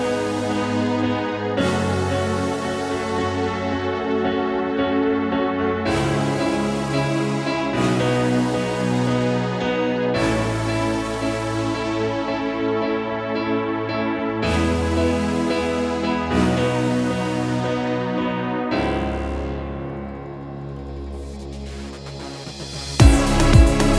no Backing Vocals Pop (2010s) 4:02 Buy £1.50